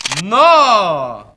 Worms speechbanks
collect.wav